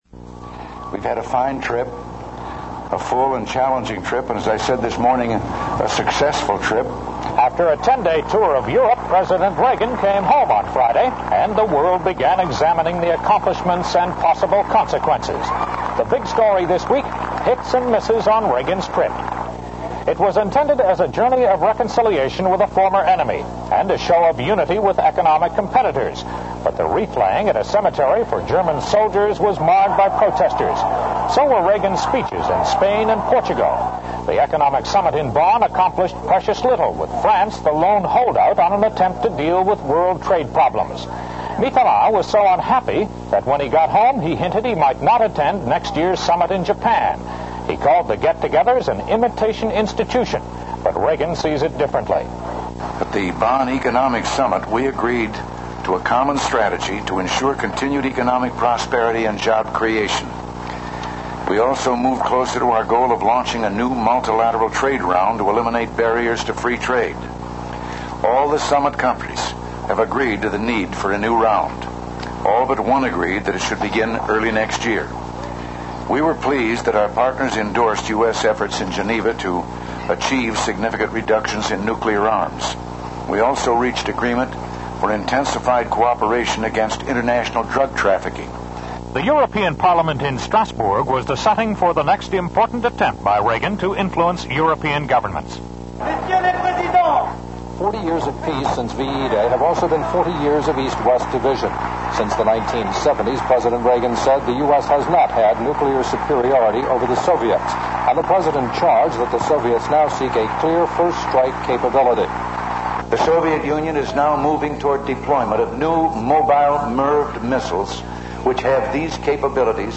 Reagan discusses the results of the Bonn Summit on economic matters.